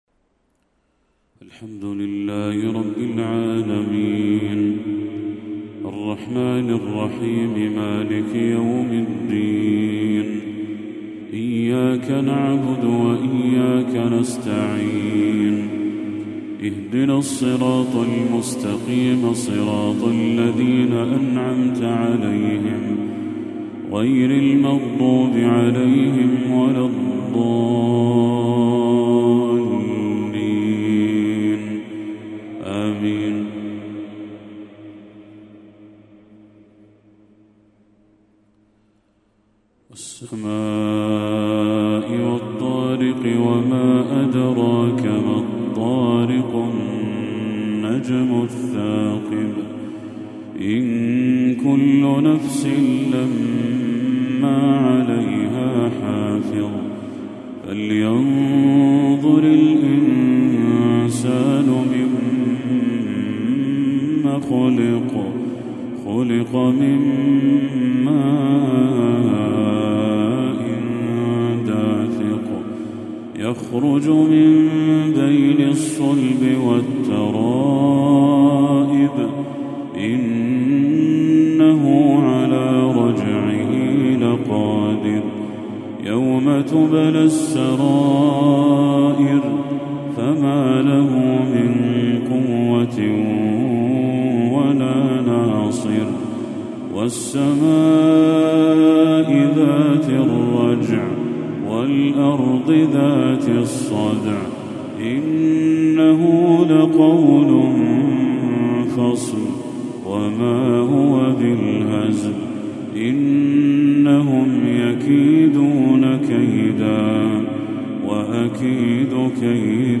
تلاوة لسورتي الطارق و المسد للشيخ بدر التركي | مغرب 15 ربيع الأول 1446هـ > 1446هـ > تلاوات الشيخ بدر التركي > المزيد - تلاوات الحرمين